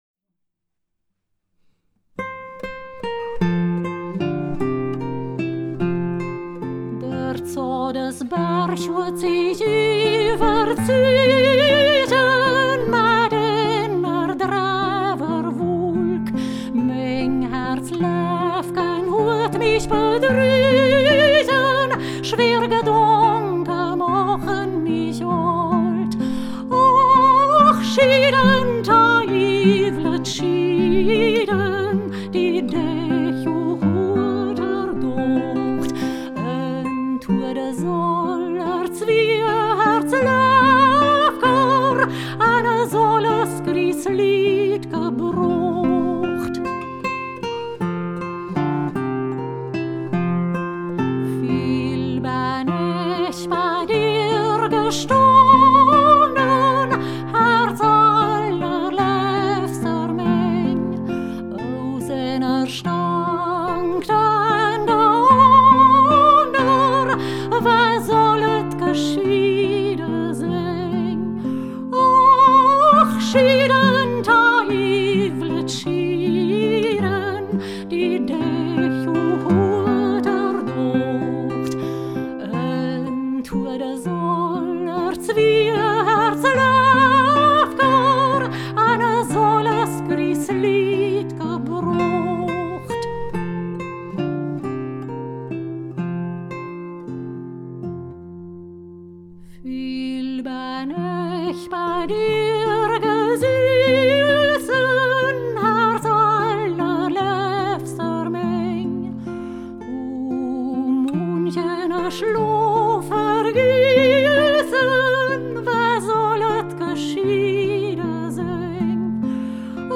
Volkslied
Ortsmundart: Hermannstadt